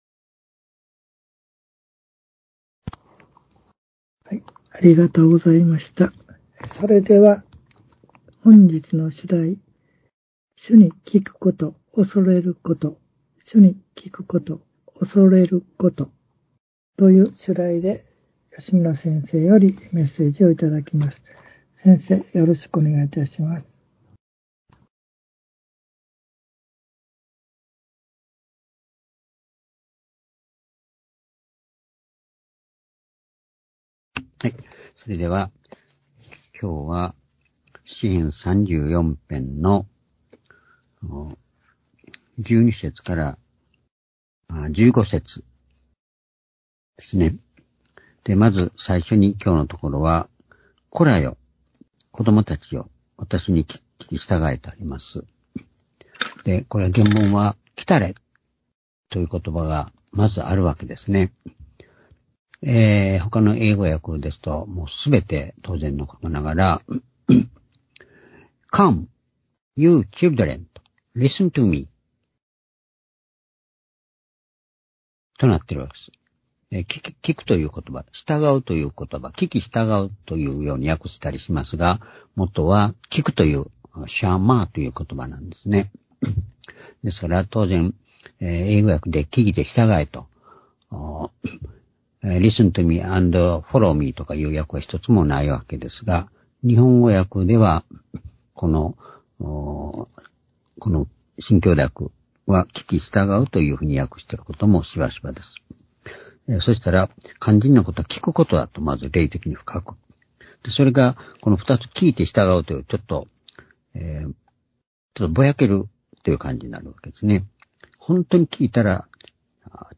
（主日・夕拝）礼拝日時 ２０２３年４月１８日（夕拝） 聖書講話箇所 「聞くこと、畏れること」 詩編34編12節～15節 ※視聴できない場合は をクリックしてください。